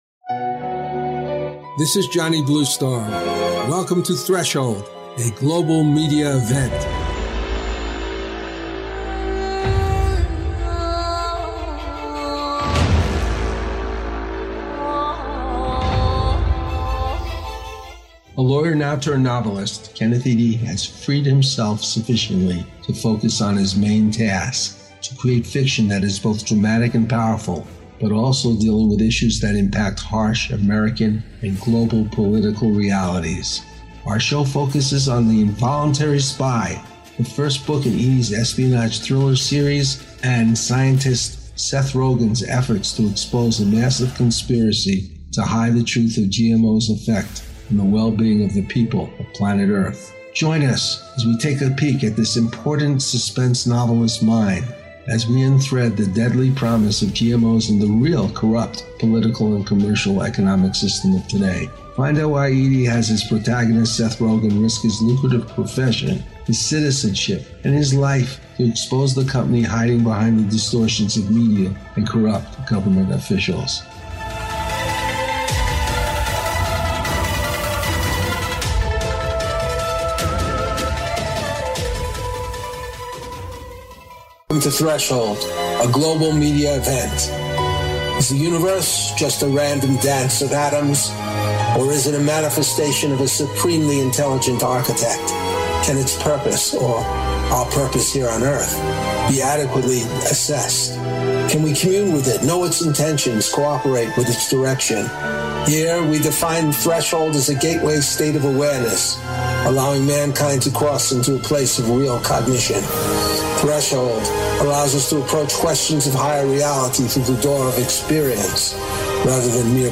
Threshold Radio is a radio talk show postulating that personal connection to a Higher Power is the most profitable way of being connected to your life, your destiny and to the Source whose purpose is embodied in the nature of things.